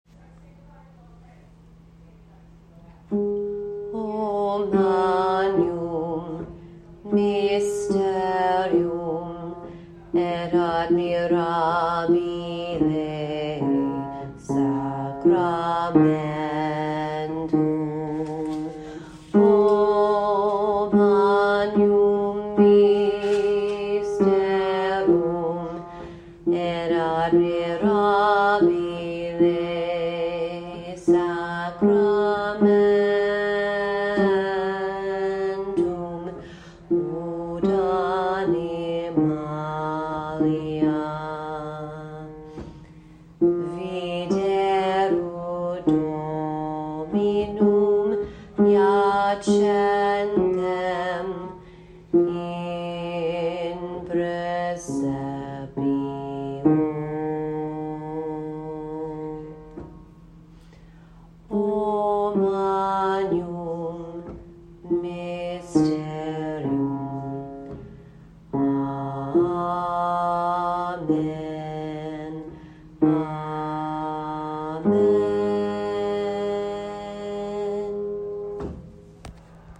O Magnum Mysterium - Baritone
Magnum - baritone.mp3